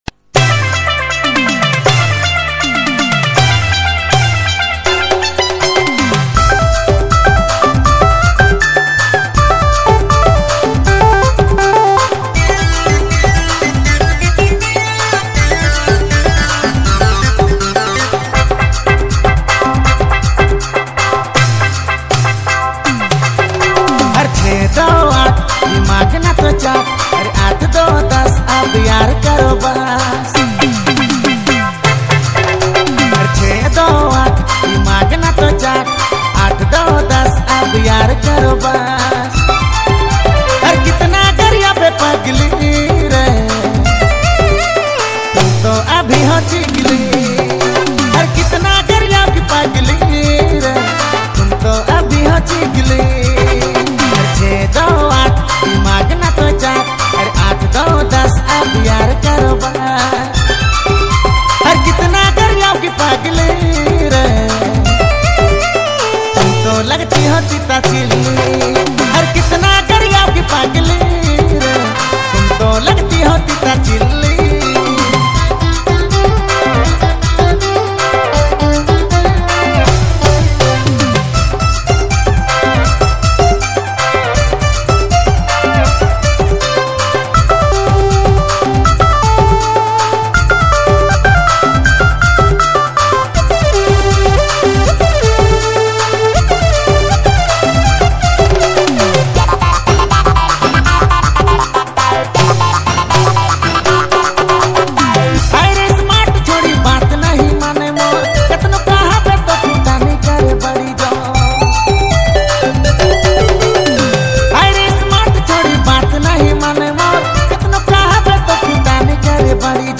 New Bewafa Dj Nagpuri Song